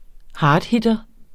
Udtale [ ˈhɑːdˌhidʌ ]